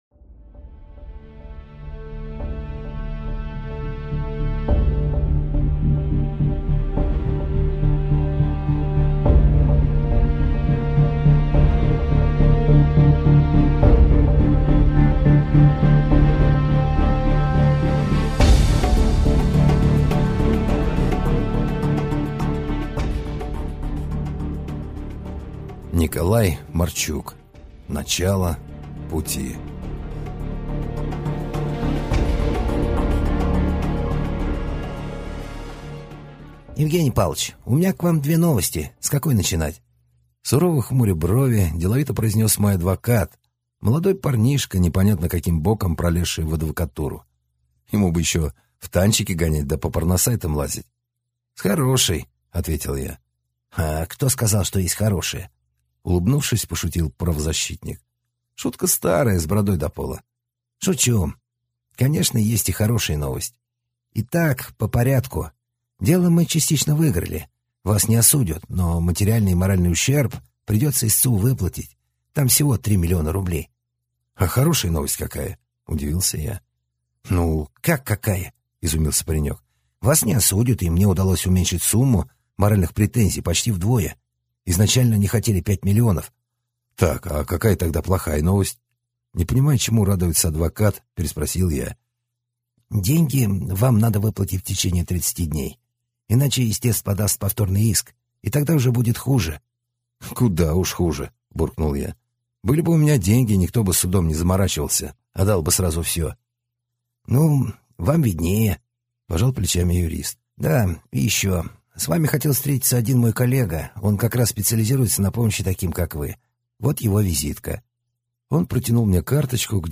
Аудиокнига Закрытый сектор. Начало пути | Библиотека аудиокниг